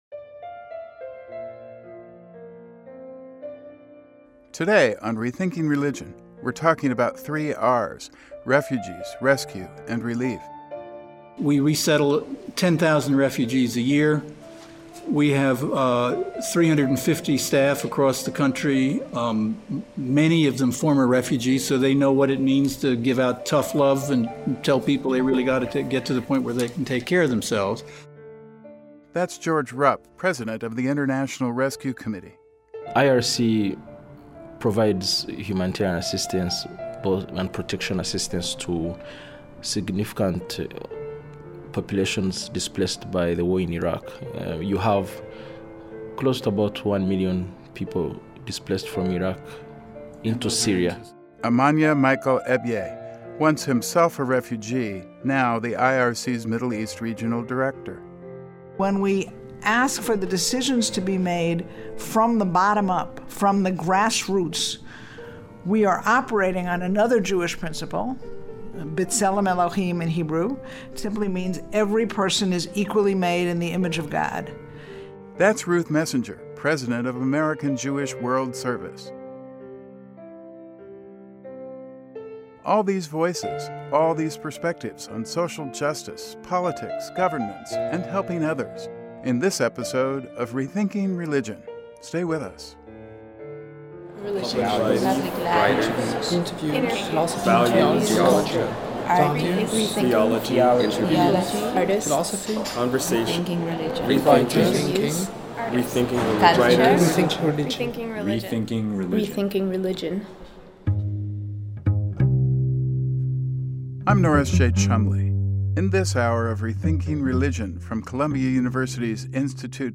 A radio episode from Rethinking Religion